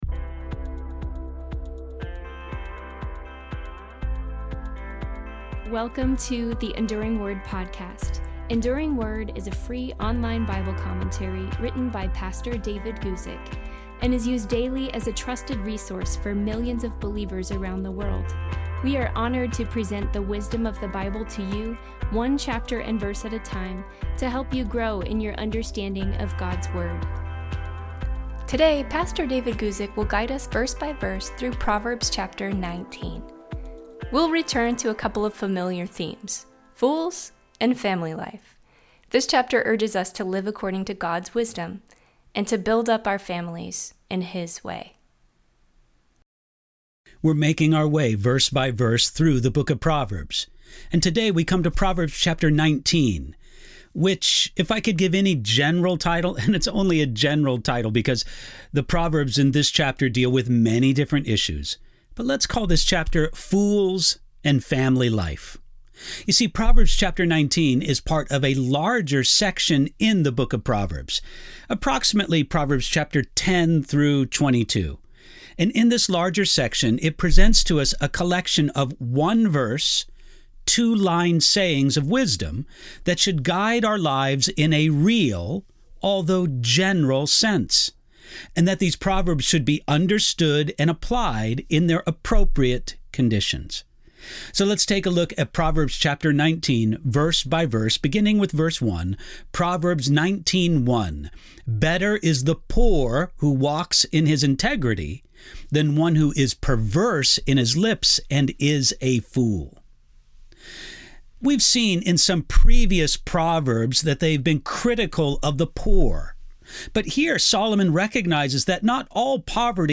Weekly Bible teaching